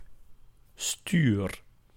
Ääntäminen
IPA: [styr]